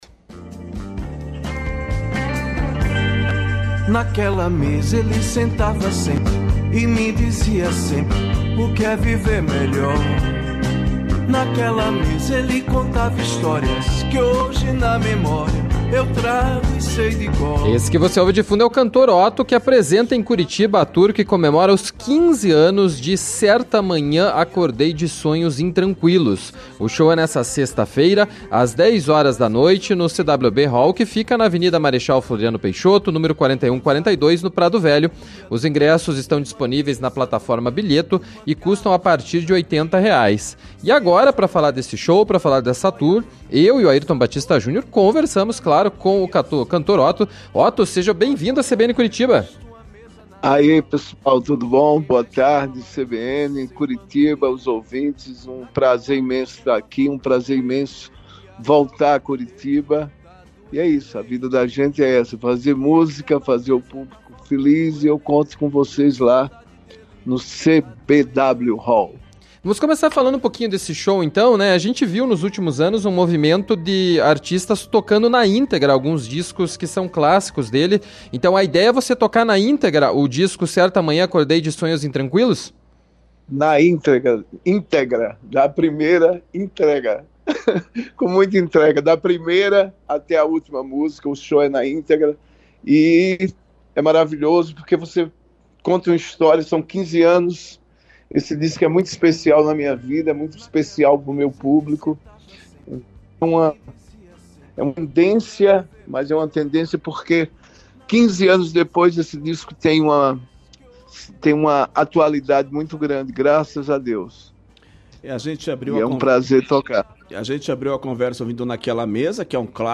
entrevista-05-11-editada.mp3